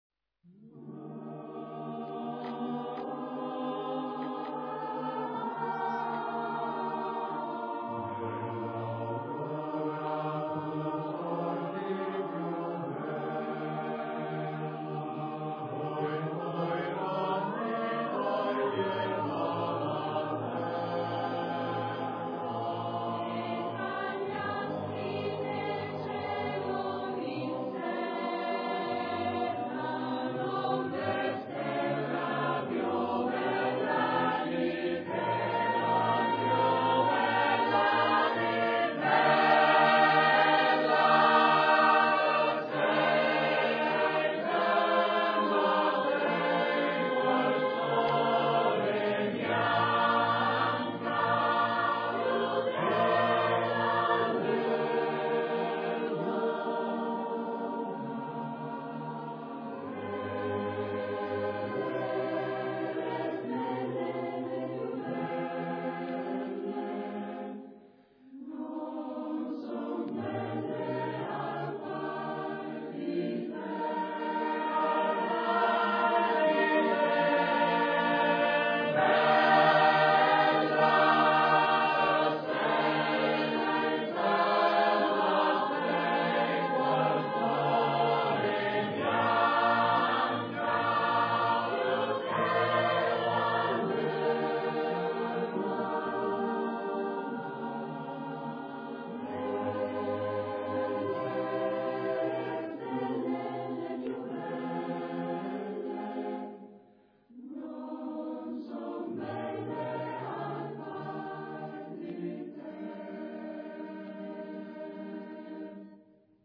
Ricerca, elaborazione, esecuzione di canti popolari emiliani
Ancora da Gaggio Montano viene questa bella melodia che riveste un tema noto in numerose regioni italiane.
voci virili